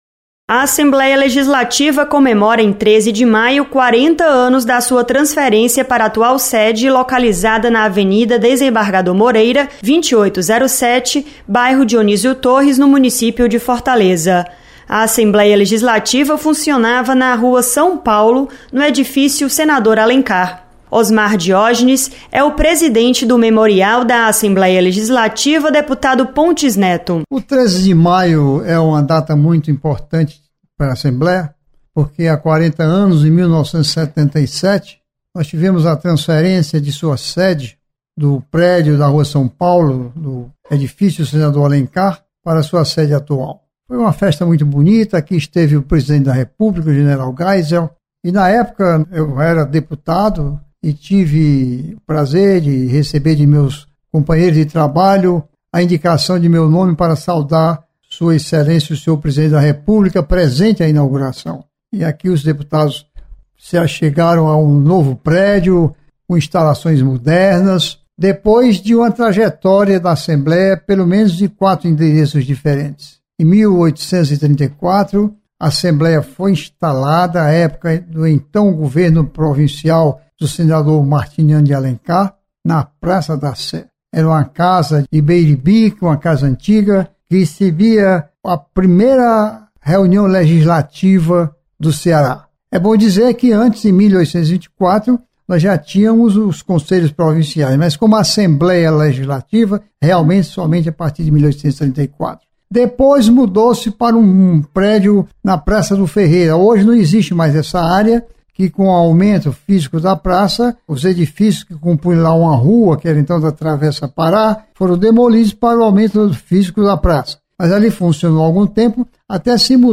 Há 40 anos, a sede do Legislativo Estadual foi transferida para o bairro Dionísio Torres. Repórter